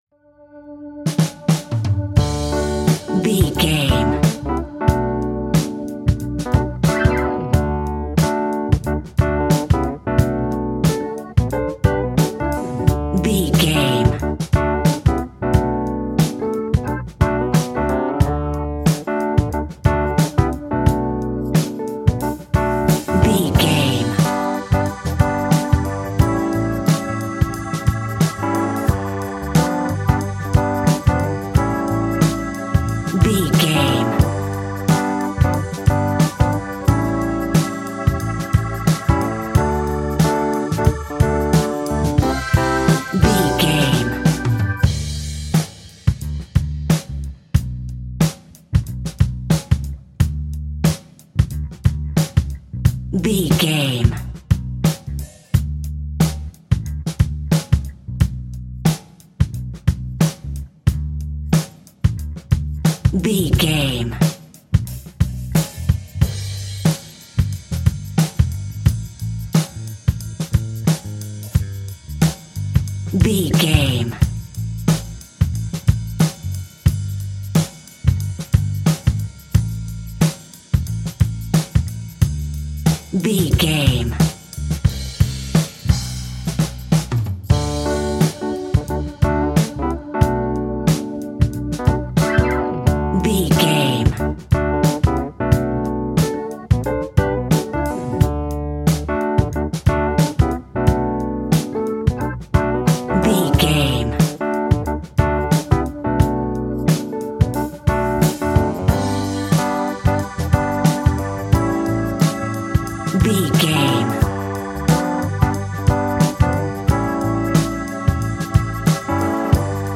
Ionian/Major
D
funky
uplifting
bass guitar
electric guitar
organ
drums
saxophone
groovy